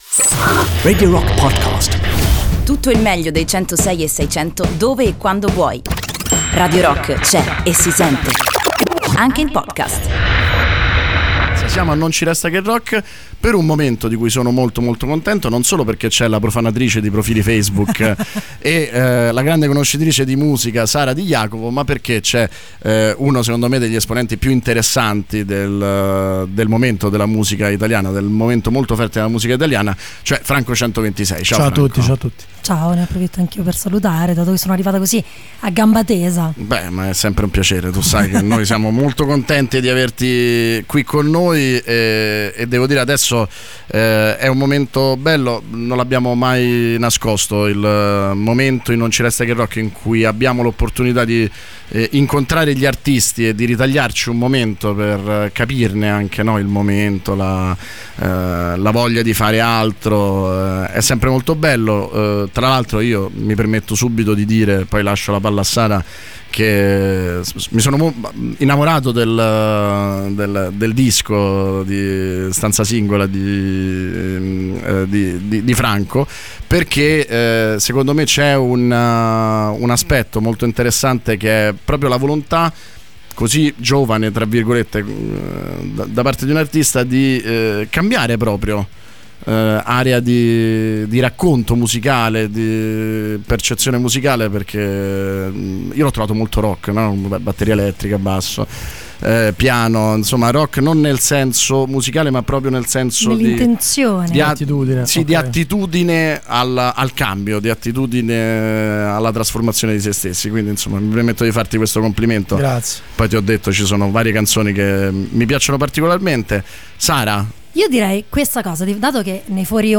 Intervista: Franco 126 - "Stanza singola Tour" (07-02-19)